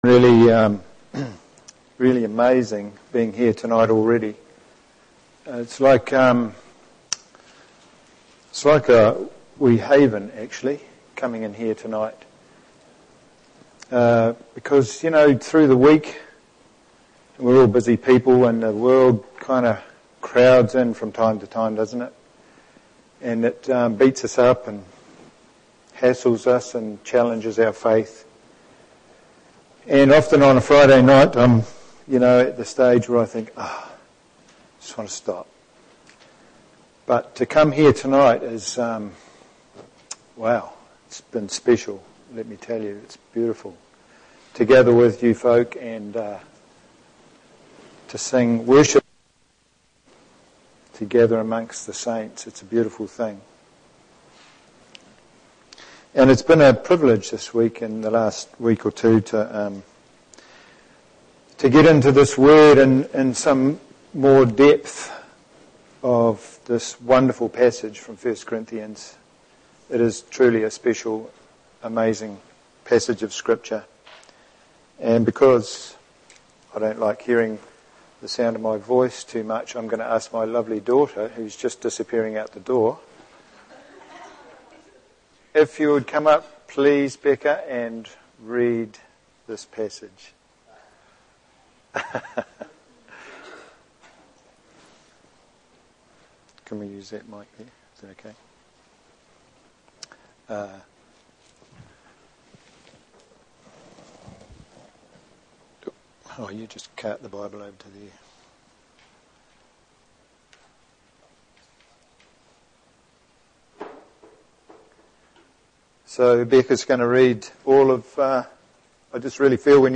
1 Corinthians 13:7 Sermon